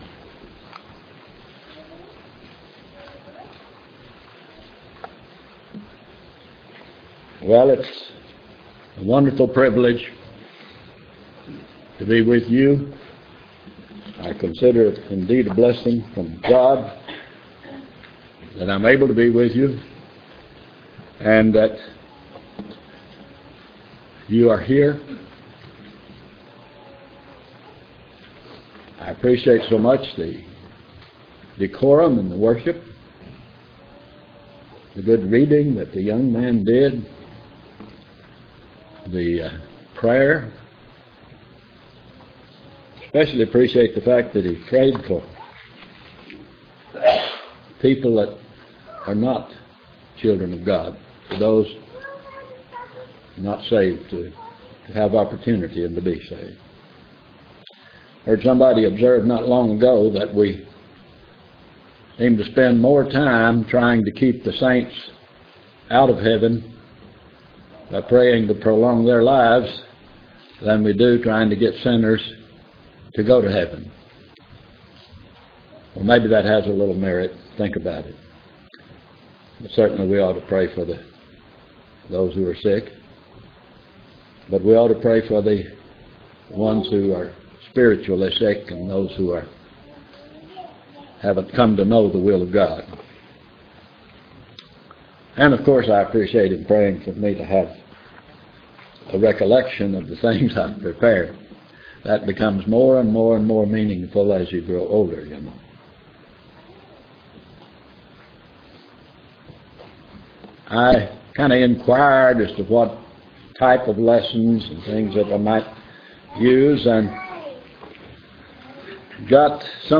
Black Creek church of Christ - Sermons Preached